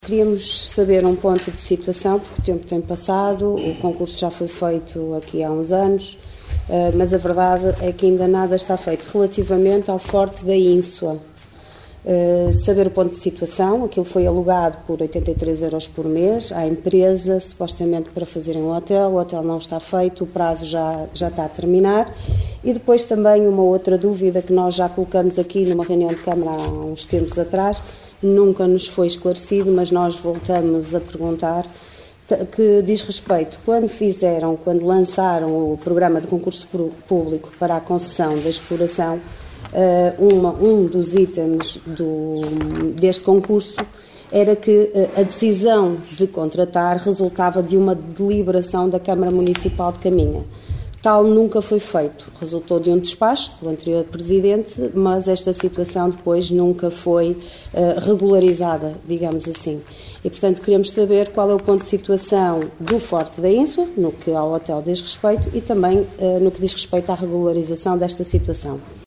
O Forte da Ínsua, alugado por 83 euros à Diverlanhoso, uma empresa privada que ali pretendia implementar um projeto turístico, nomeadamente um Hotel, no âmbito do programa REVIVE, foi um dos assuntos abordados na reunião de Câmara de ontem pelos vereadores da Coligação O Concelho em Primeiro (OCP).
A vereadora Liliana Silva da OCP pediu à Câmara que fizesse o ponto de situação em relação ao futuro daquele monumento que está neste momento na posse de uma empresa privada sem que o executivo tivesse aprovado em reunião de Câmara essa cedência, feita por despacho do presidente de então.